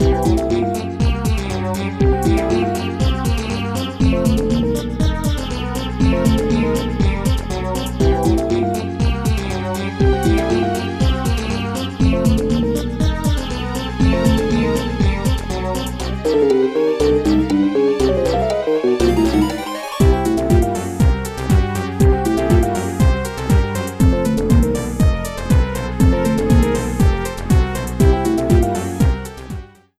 An arrangement